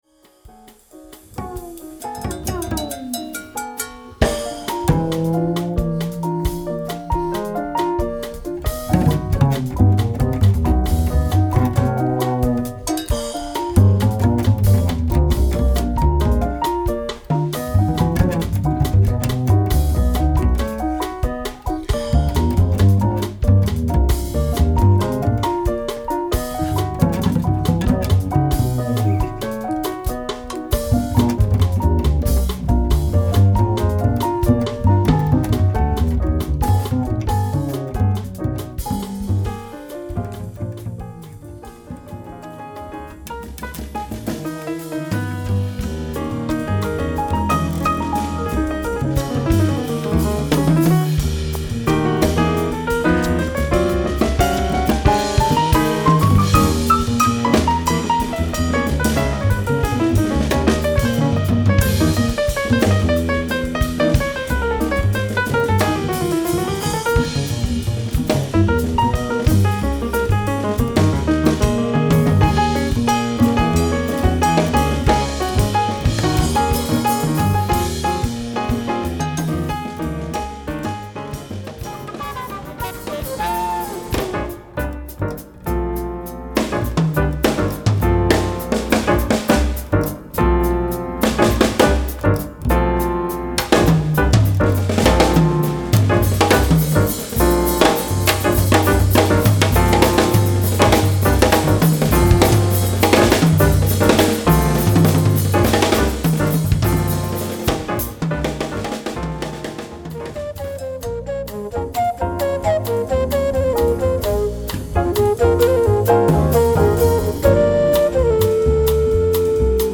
Afro-Cubain – Latino-Jazz
basse
piano
batterie
saxophone
flûtes